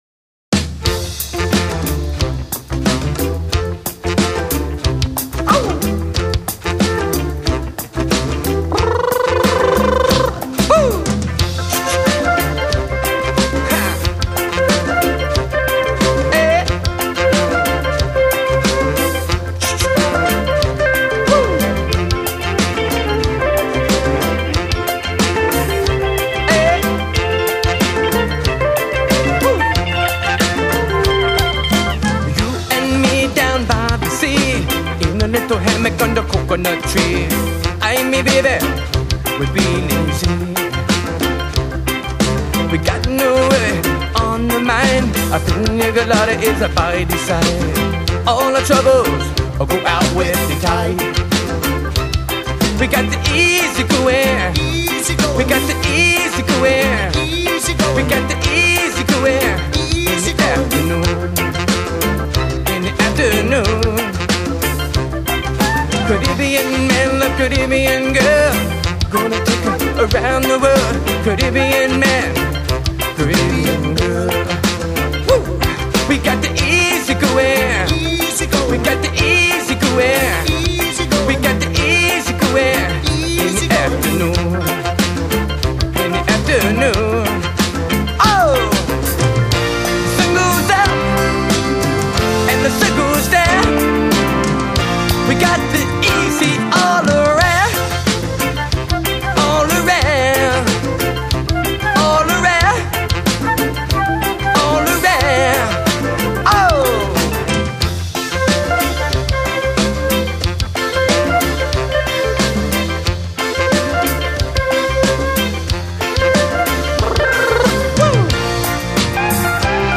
TEX-MEX